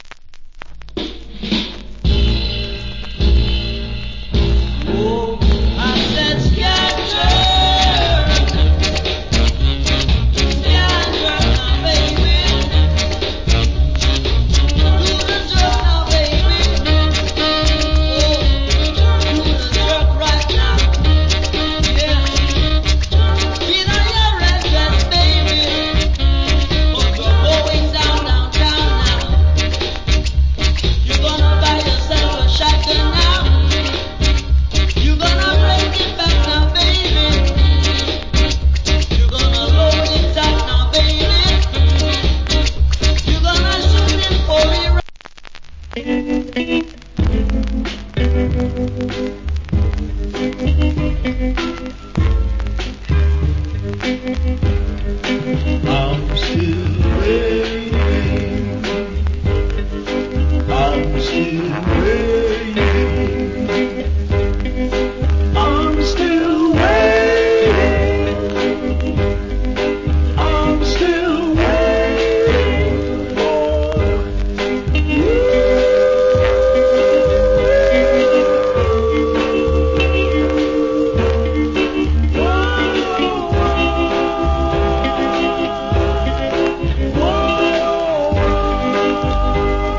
Ska Vocal.